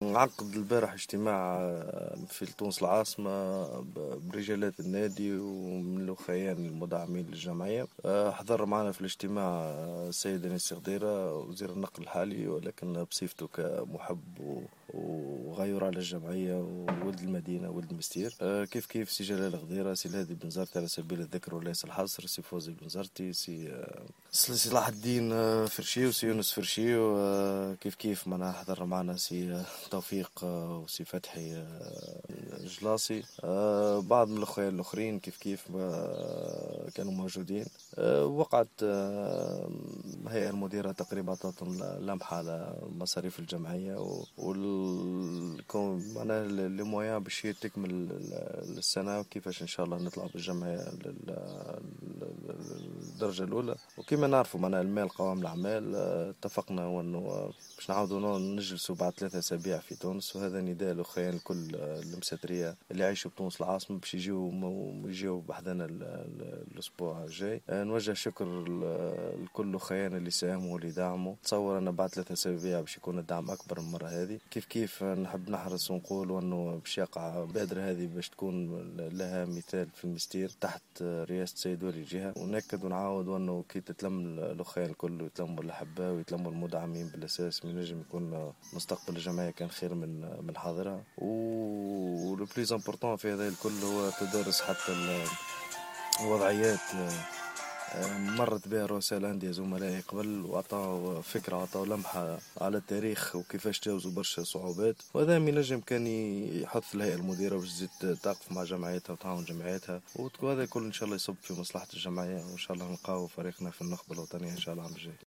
تصريح لمراسل جوهرة أف أم